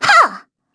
Xerah-Vox_Attack3_kr.wav